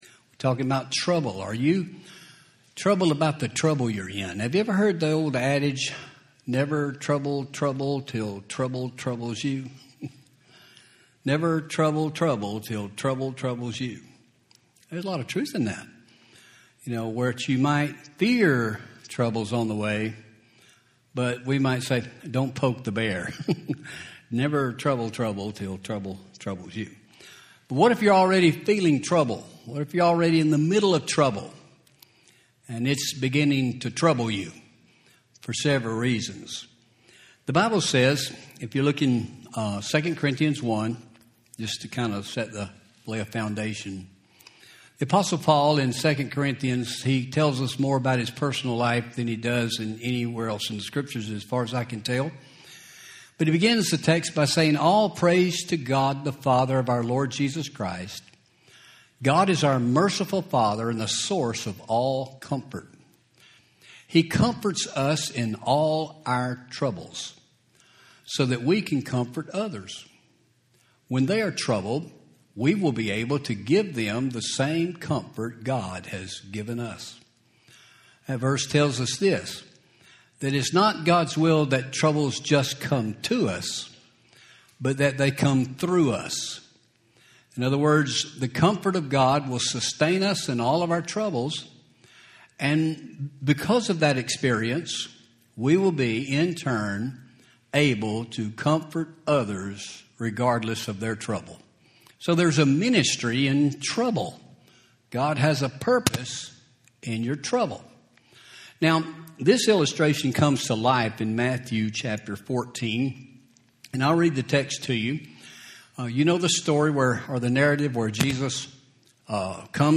Home › Sermons › Are You Troubled About the Trouble Your In